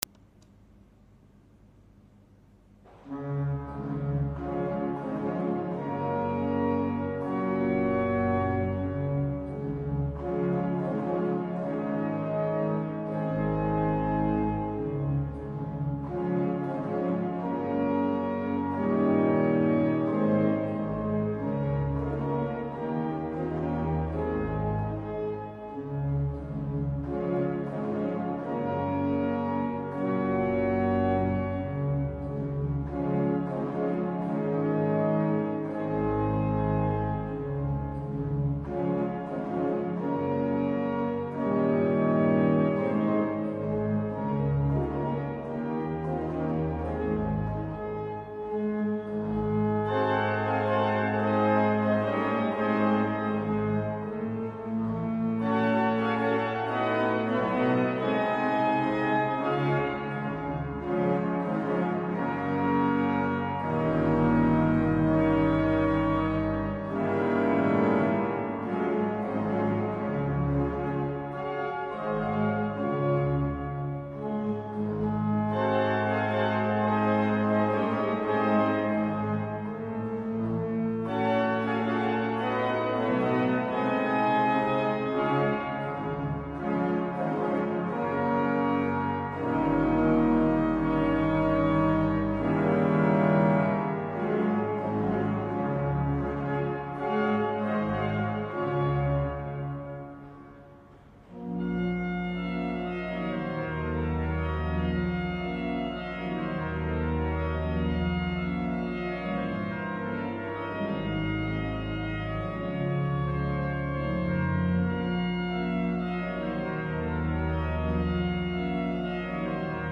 L'Organo Rotelli-Varesi della chiesa della missione di S. Vincenzo de' Paoli - Napoli
La registrazione è amatoriale e ha il solo scopo di presentare qualche caratteristica fonica dello strumento.